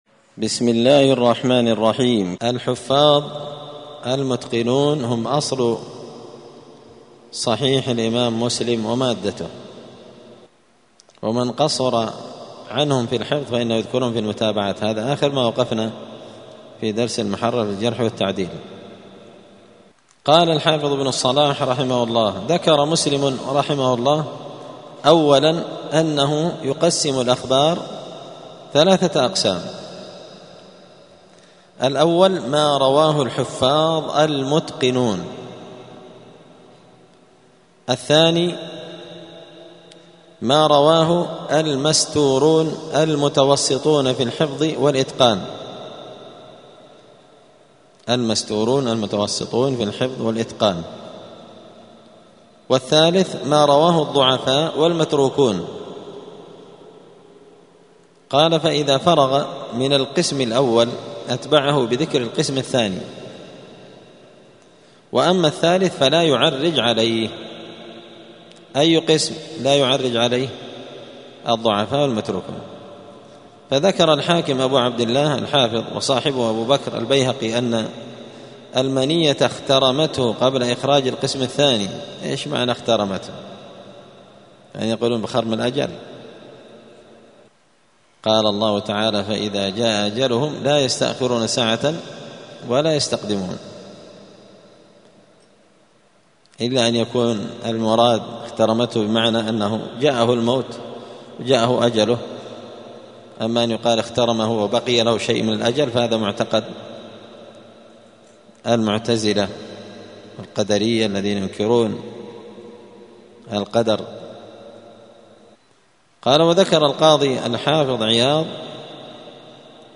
الأربعاء 29 شوال 1445 هــــ | الدروس، المحرر في الجرح والتعديل، دروس الحديث وعلومه | شارك بتعليقك | 30 المشاهدات
دار الحديث السلفية بمسجد الفرقان بقشن المهرة اليمن